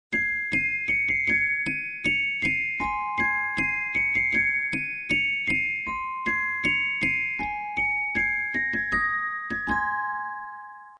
Coffret : Argent massif 925 Dimensions : 3.2 x 2.8 x 1.8 cm Mouvement : 1x 17 lames REUGE© 390
Boîte à musique suisse – REUGE© Nom : Stellina